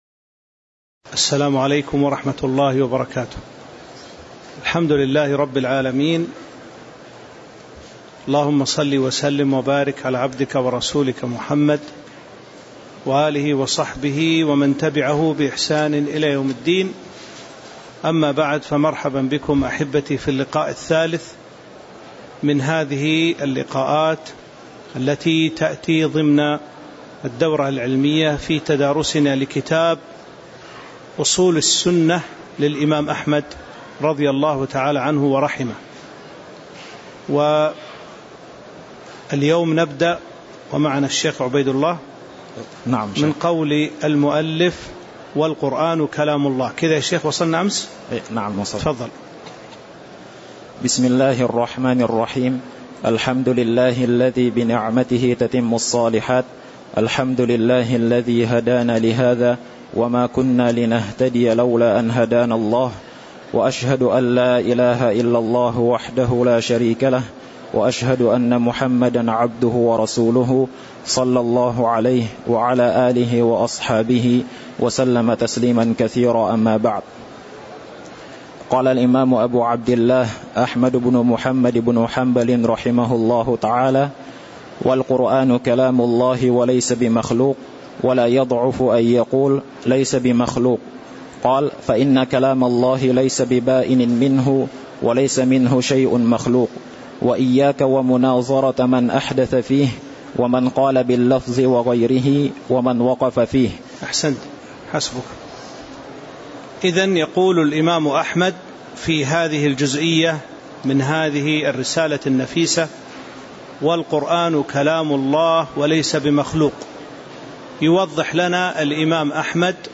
تاريخ النشر ٢٦ صفر ١٤٤٥ هـ المكان: المسجد النبوي الشيخ